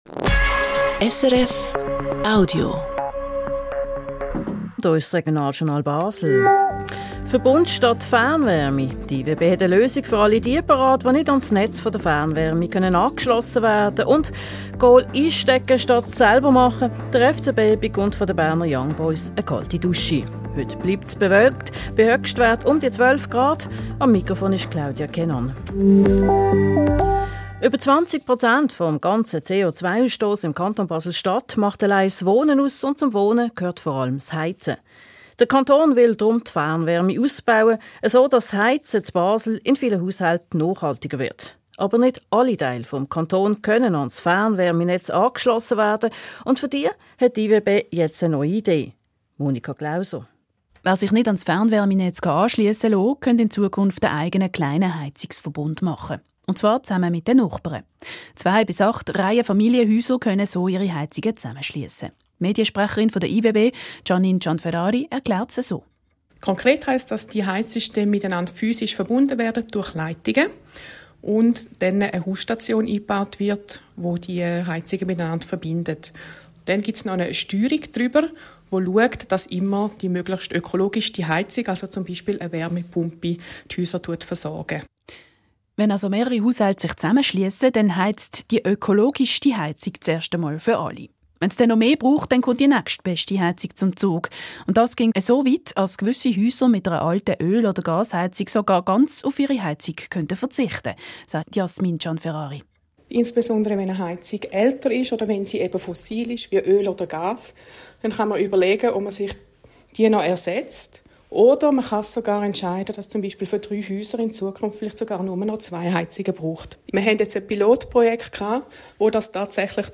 Beitrag im Regionaljournal SRF